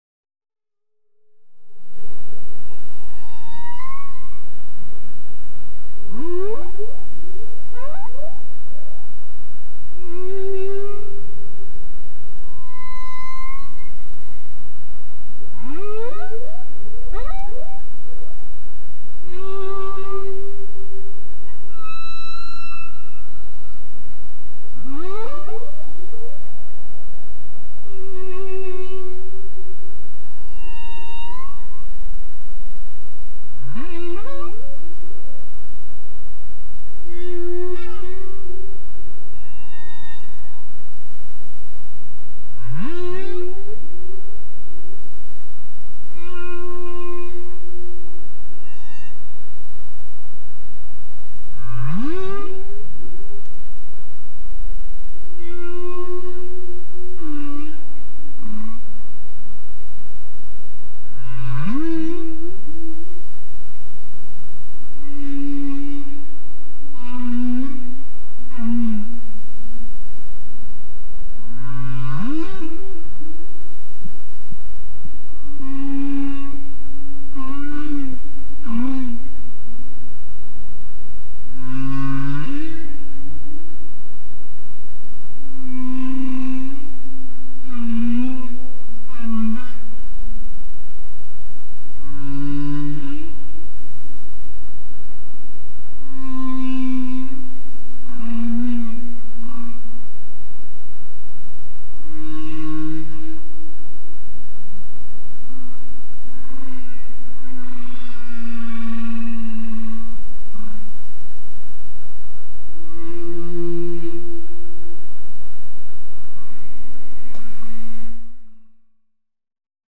By comparison, the different sounds made by humpback whales, which are famous for being the most altruistic animal.
humpback-whale-song-from-monterey-bay.mp3